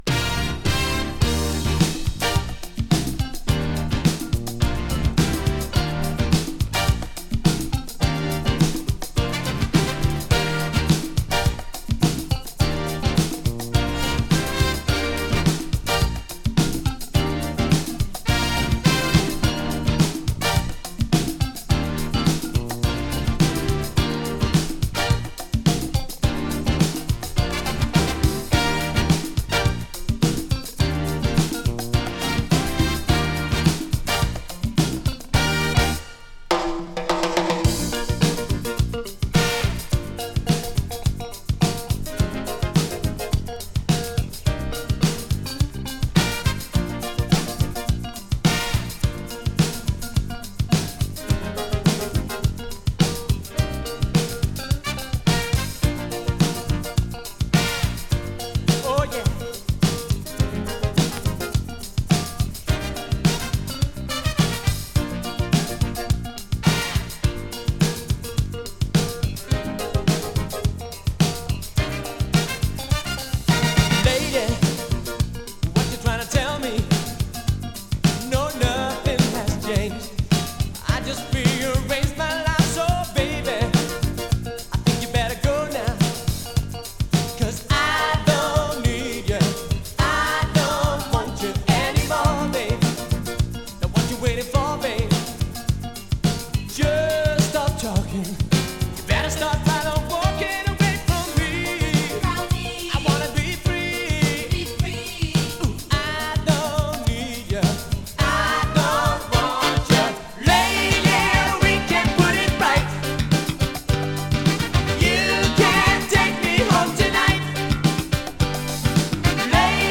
Datch Pop Disco!
エモーショナルな男性ヴォーカルとポップなメロディが印象的なオランダ産ディスコナンバー！
【DISCO】【POPS】